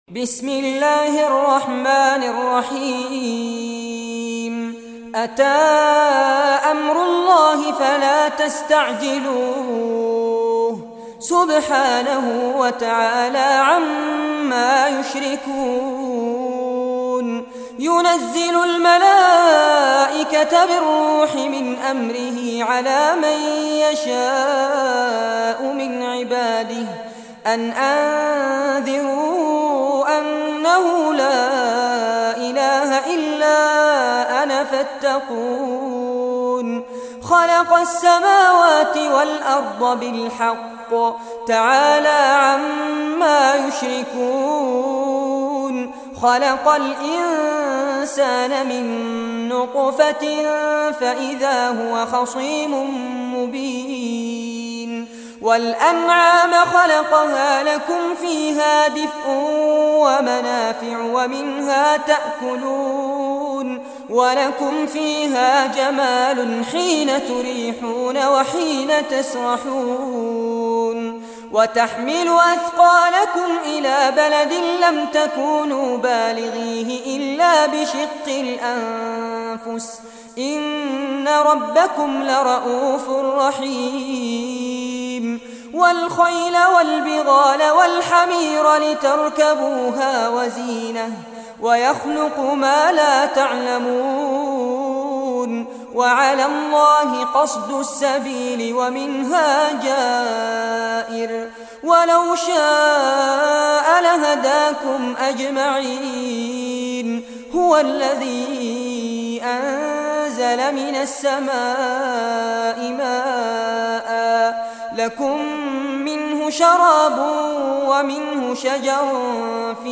Surah An-Nahl Recitation by Sheikh Fares Abbad
Surah An-Nahl, listen or play online mp3 tilawat / recitation in Arabic in the beautiful voice of Sheikh Fares Abbad.
16-surah-nahl.mp3